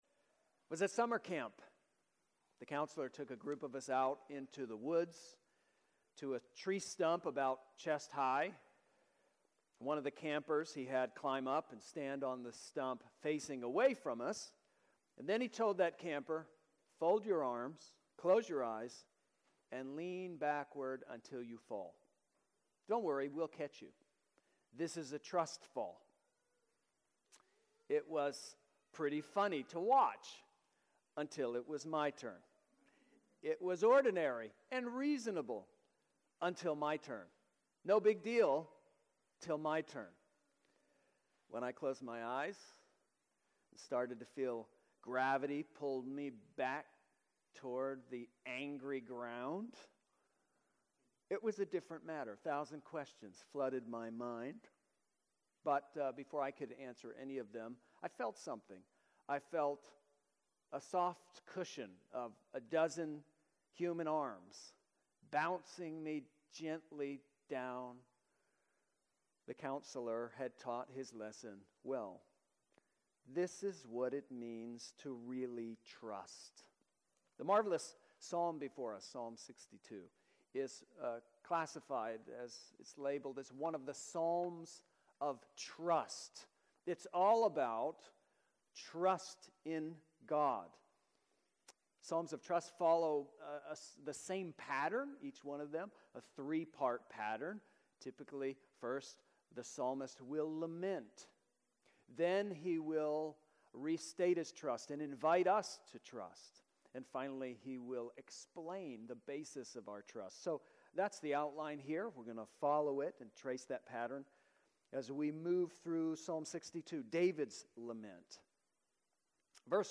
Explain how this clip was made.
Easter Sunday 2026